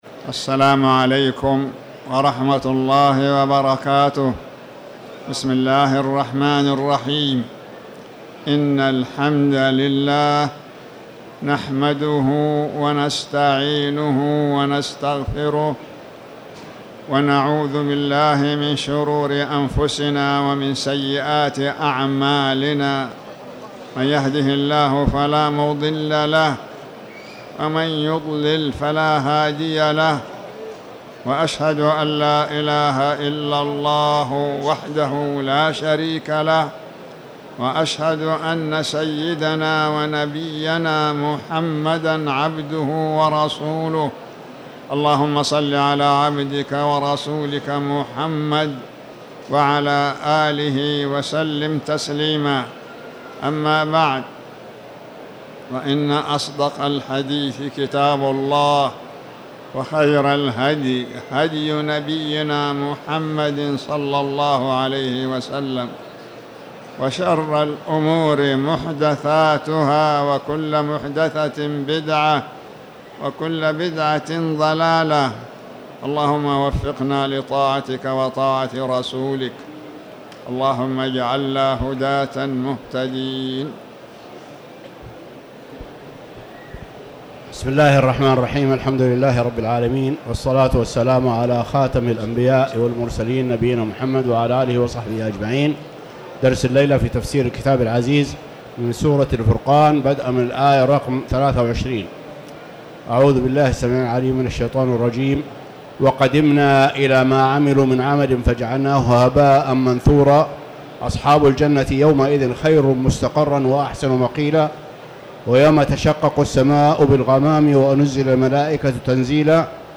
تاريخ النشر ١١ رجب ١٤٤٠ هـ المكان: المسجد الحرام الشيخ